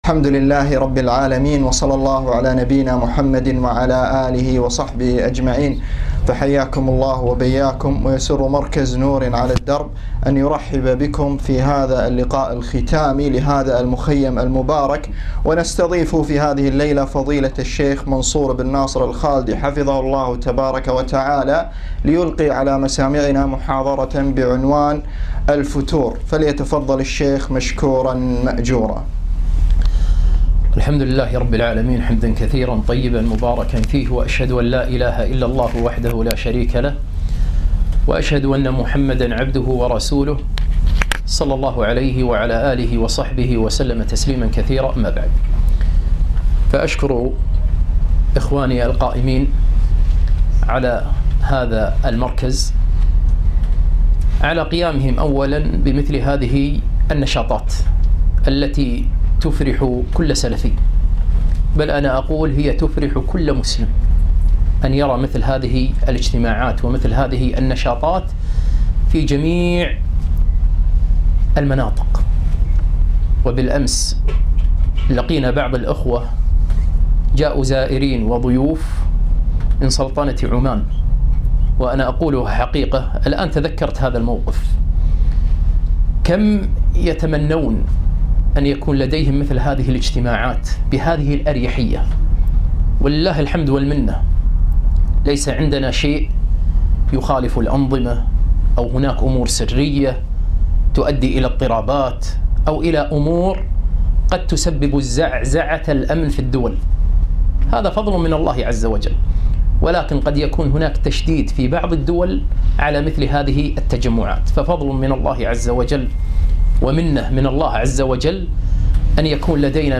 محاضرة - الفتور